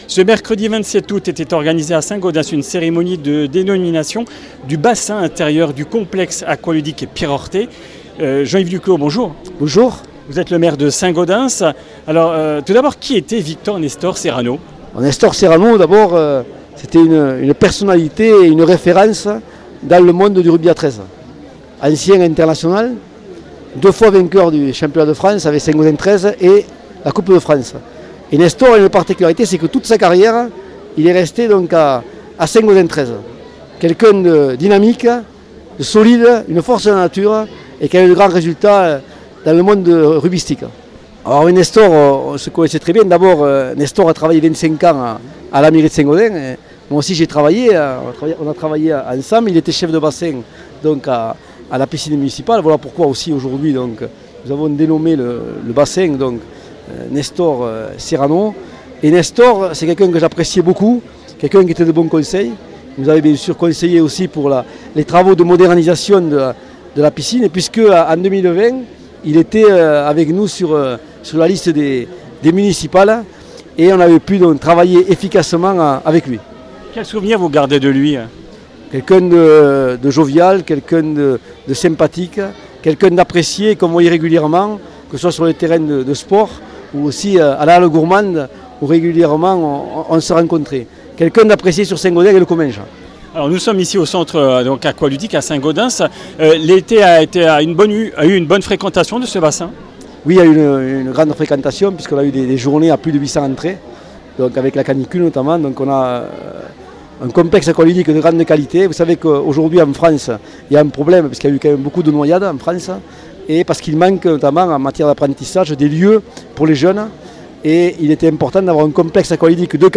Comminges Interviews du 03 sept.